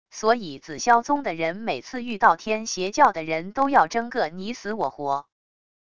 所以紫宵宗的人每次遇到天邪教的人都要争个你死我活wav音频生成系统WAV Audio Player